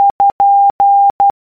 Ð_Morse_Code.oga.mp3